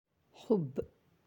(hubb)
hubb.aac